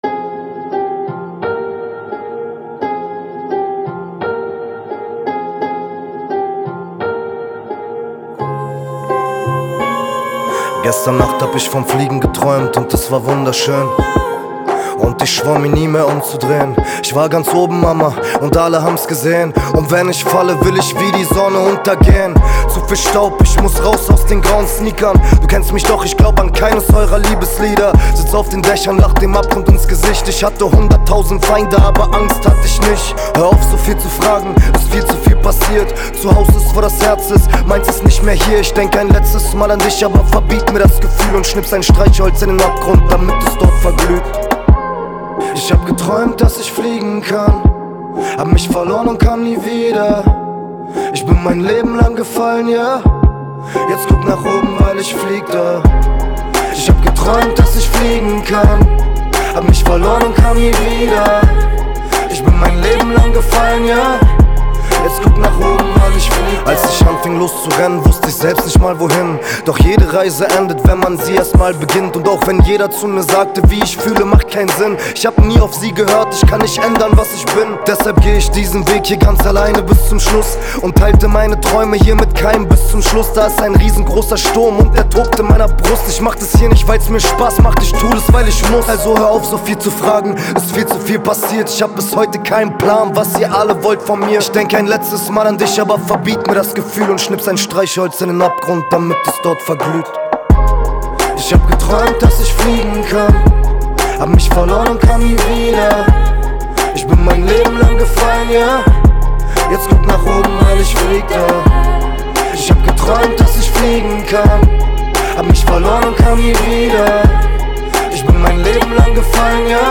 это трек в жанре поп с элементами электронной музыки